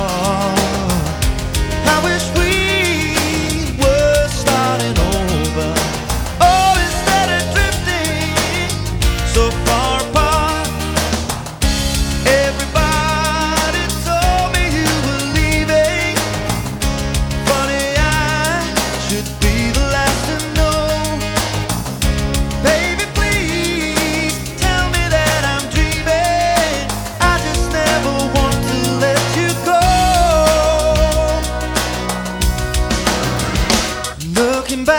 Жанр: Поп музыка / Рок
Rock, Pop, Pop, Rock, Adult Contemporary